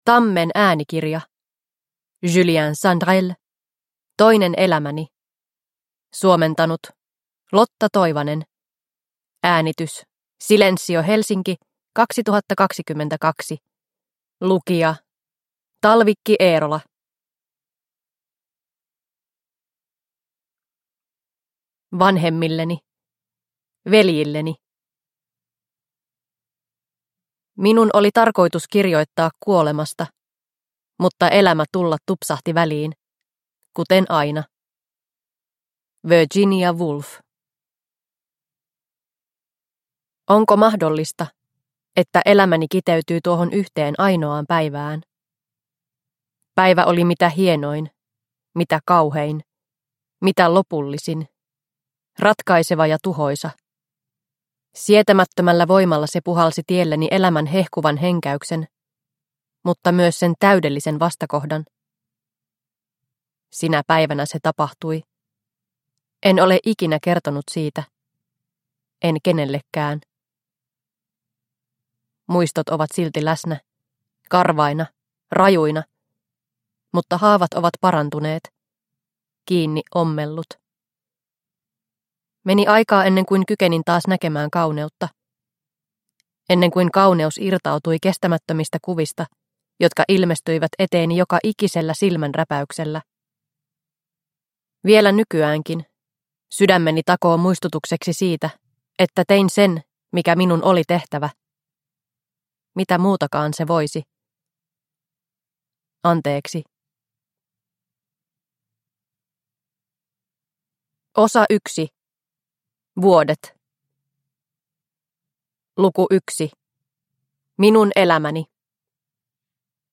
Toinen elämäni – Ljudbok – Laddas ner